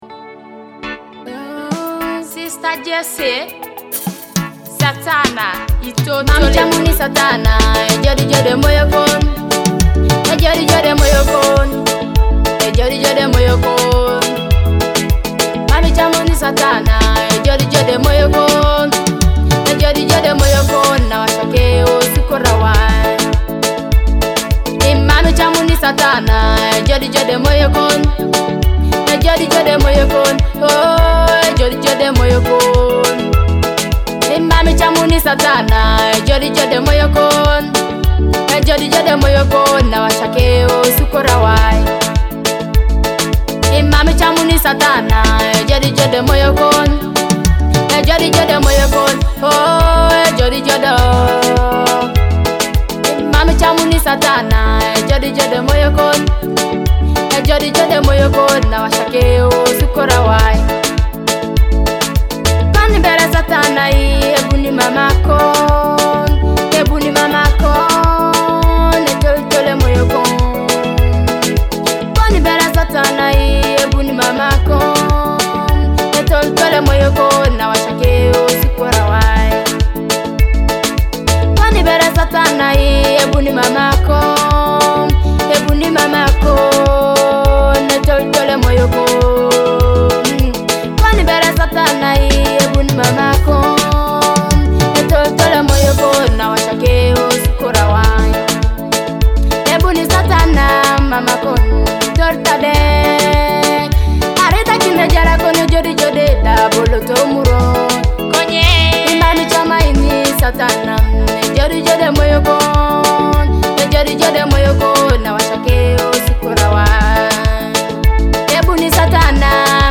a Teso gospel praise song inspiring faith